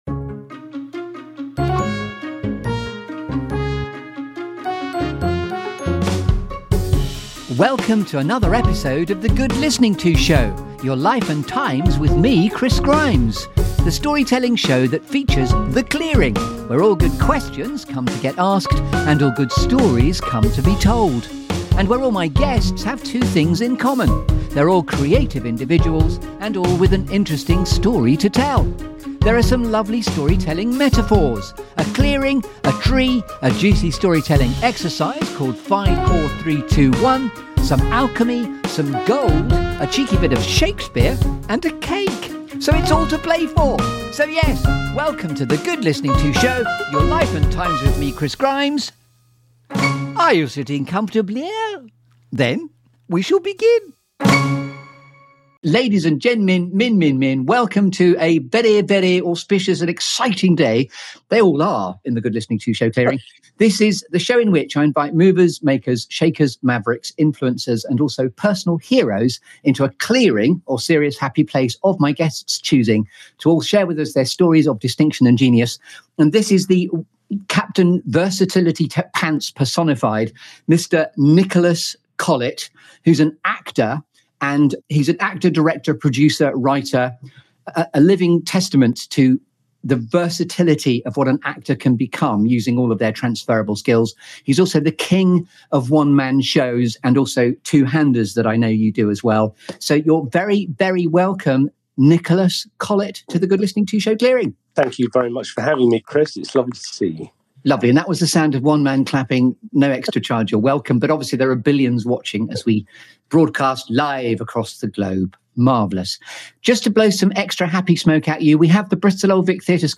This feel-good Storytelling Show that brings you ‘The Clearing’.